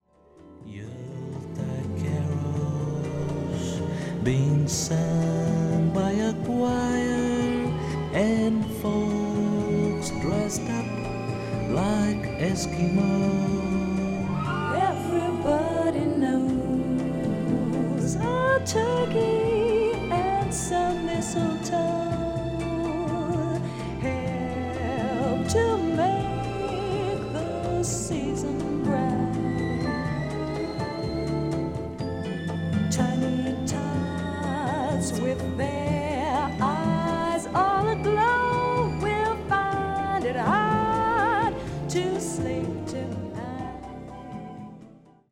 ハッピーでスウィートなウィンター・ソングが詰まっています。